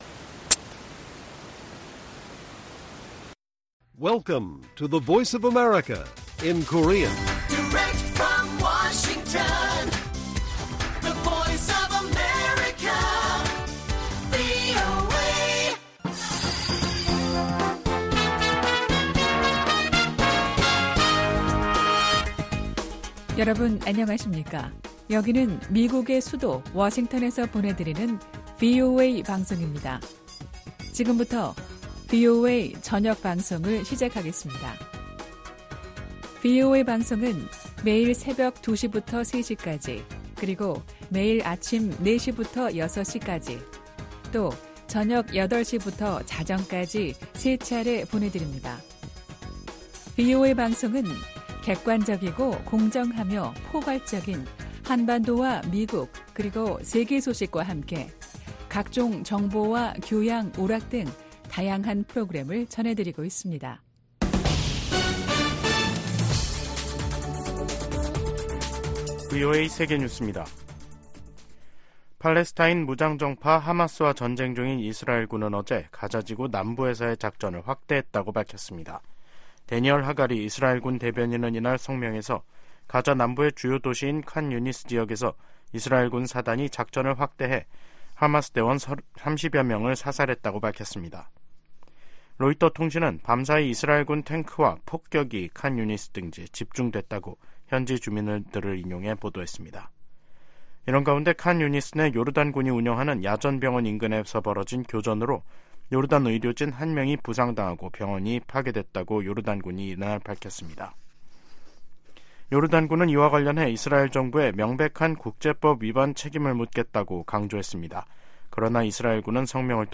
VOA 한국어 간판 뉴스 프로그램 '뉴스 투데이', 2024년 1월 18일 1부 방송입니다. 미국은 북한과 러시아의 무기 거래는 안보리 결의 위반이라고 비판하고, 북한 지도부에 외교에 복귀하라고 촉구했습니다. 미한일 북 핵 수석대표들이 북한에 긴장을 고조시키는 언행과 도발, 무모한 핵과 미사일 개발을 중단할 것을 요구했습니다. 국제 기독교선교단체 '오픈도어스'가 2024 세계 기독교 감시 보고서에서 북한을 기독교 박해가 가장 극심한 나라로 지목했습니다.